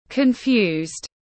Lúng túng tiếng anh gọi là confused, phiên âm tiếng anh đọc là /kən’fju:zd/